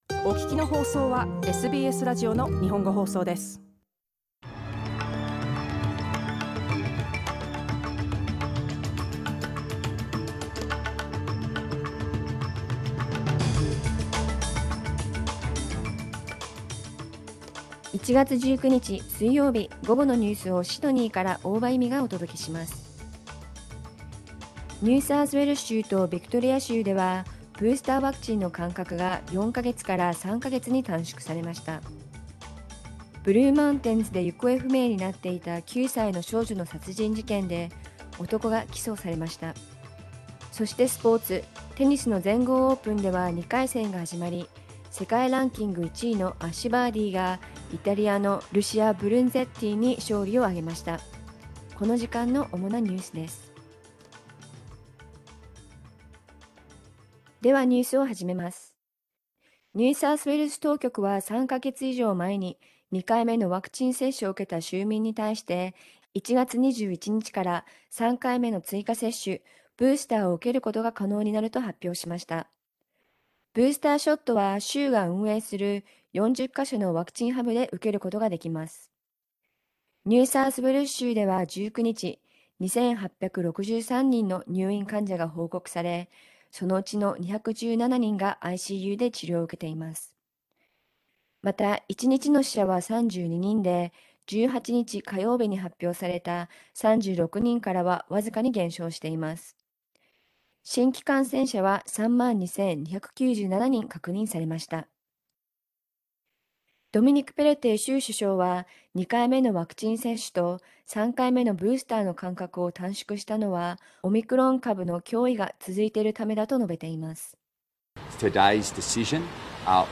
1月19日午後のニュース
Afternoon news in Japanese, 19 January 2022